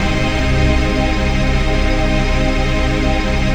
DM PAD2-27.wav